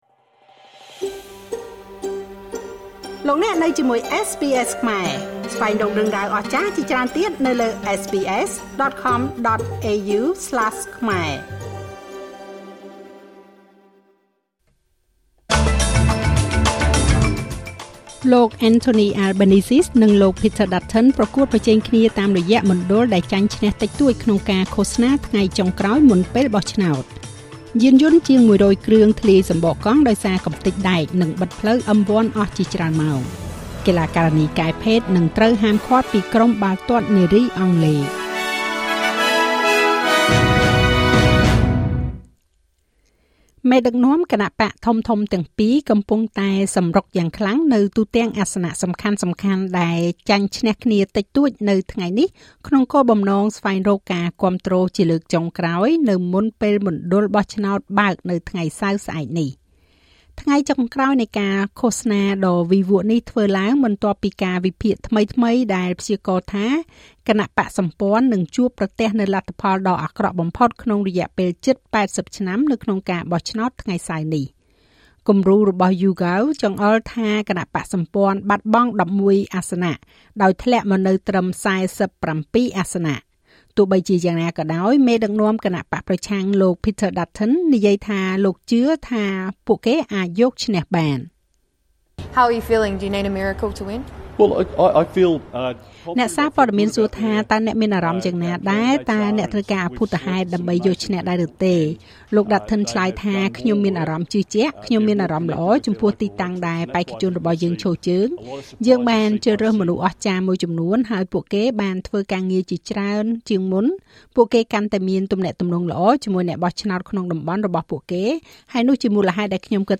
នាទីព័ត៌មានរបស់SBSខ្មែរ សម្រាប់ថ្ងៃសុក្រ ទី២ ខែឧសភា ឆ្នាំ២០២៥